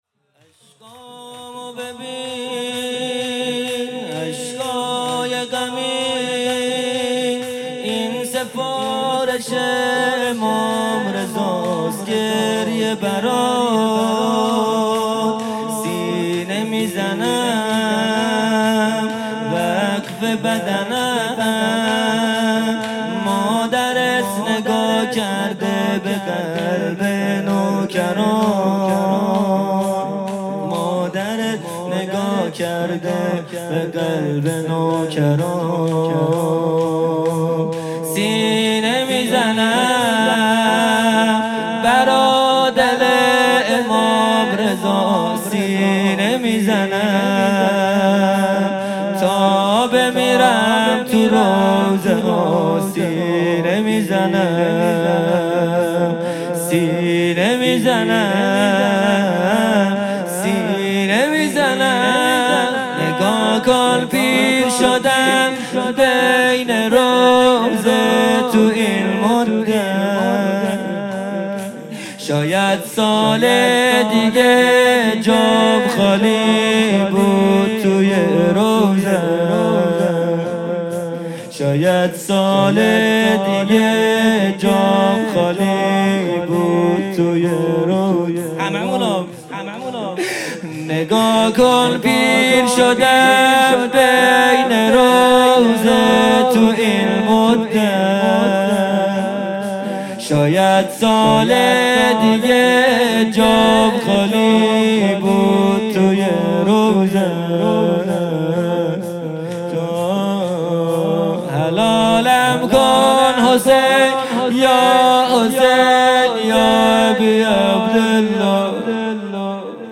خیمه گاه - هیئت بچه های فاطمه (س) - شور | چشمامو ببین| ۱۵ مهر ماه ۱۴۰۰
جلسه‌ هفتگی | شهادت امام رضا(ع)